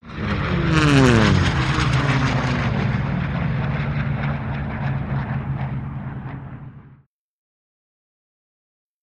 WW2 Fighters|P-38|Single
Airplane P-38 Pass By Fast Left To Right